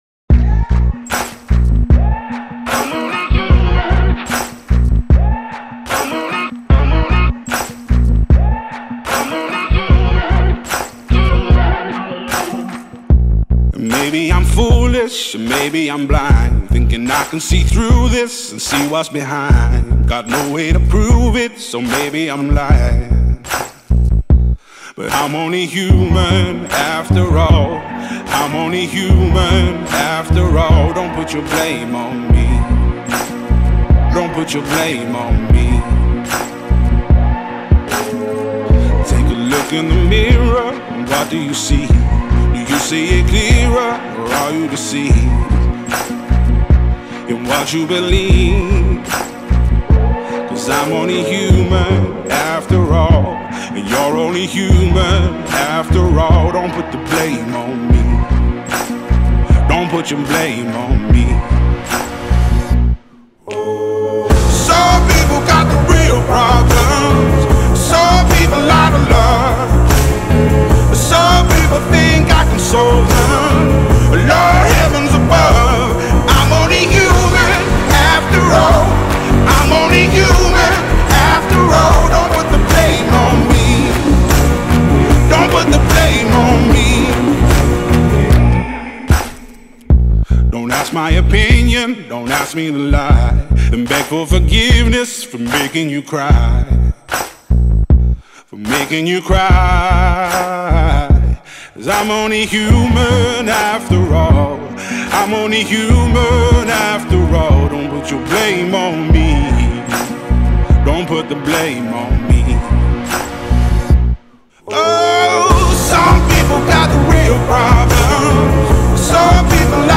خواننده بریتانیایی با صدای خشن و سول‌محور
یک اثر برجسته سول و بلوز با ترکیبی از هیپ‌هاپ و گاسپل است.
Soul, Blues, Hip-Hop